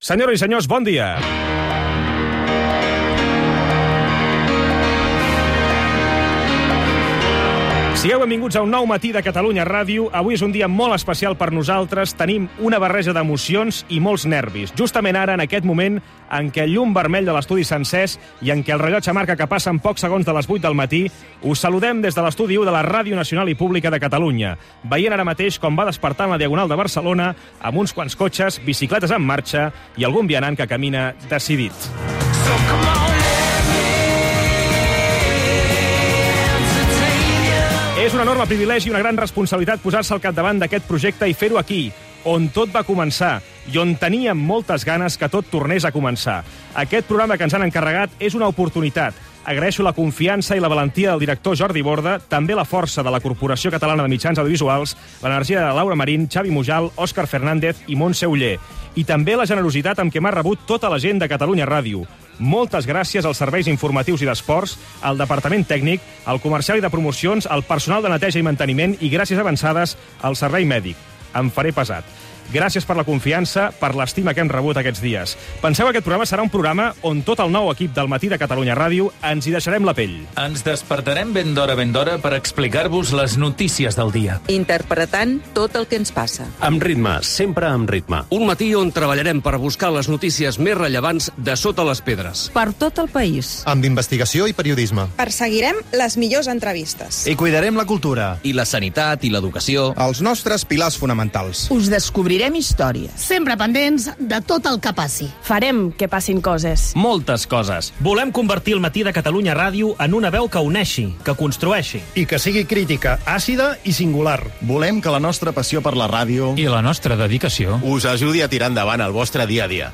Salutació a l'inici de l'hora, "collage" de veus expressant la voluntat de l'equip del programa, record a professionals del passat de l'emissora. Indicatiu del programa, resum informatiu: aiguats al Delta de l'Ebre, alerta per mal temps a Madrid, vals d'ajuda escolar, agressió sexual a Sabadell, enquesta sobre l'opinió política espanyola, mort de la filòloga Carme Junyent, Gabon, resultats de la lliga de futbol masculí.
Gènere radiofònic Info-entreteniment